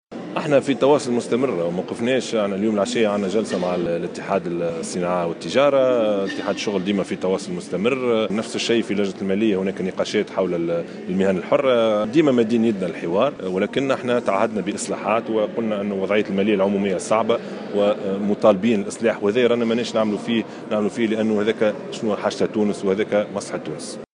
قال رئيس الحكومة يوسف الشاهد في تصريح
خلال إشرافه اليوم الجمعة 2 ديسمبر 2016 على افتتاح أشغال المؤتمر الوطني الحادي عشر لمركز المسيرين الشبان بتونس بمتحف باردو